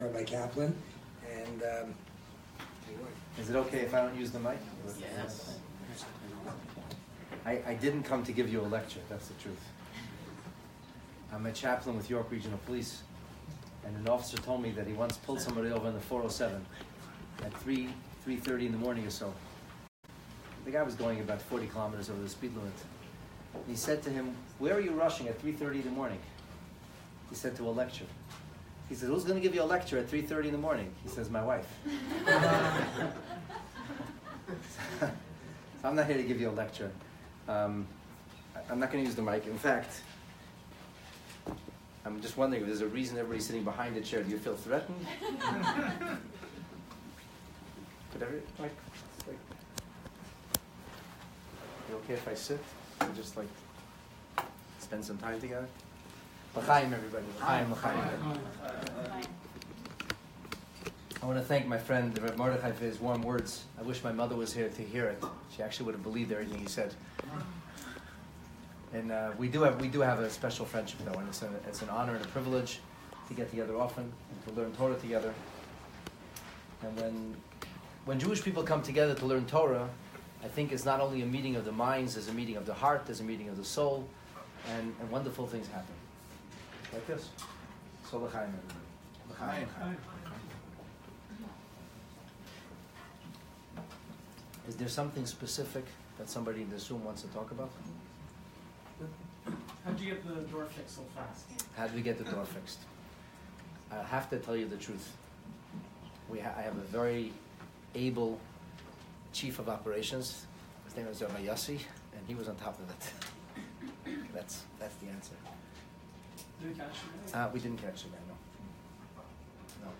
The Pesach Seder: A conversation on Rhyme, Reason & Relevancy